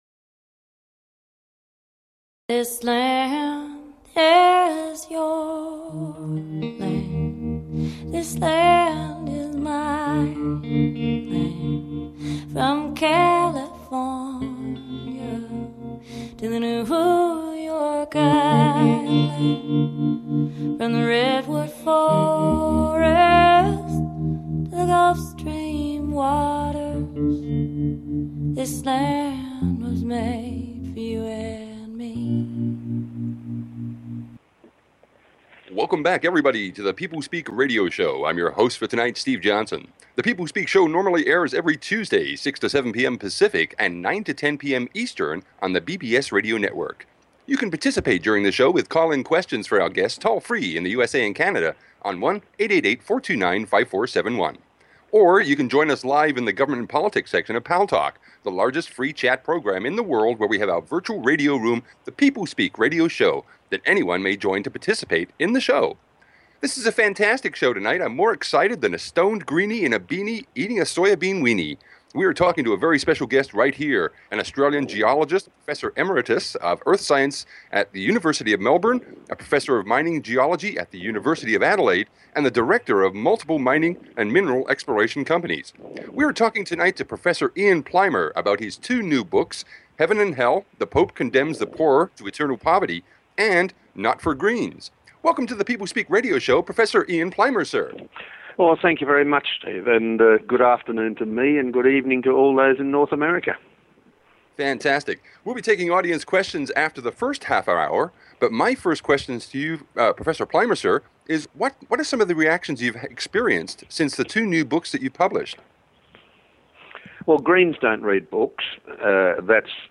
Guest, Professor Ian Plimer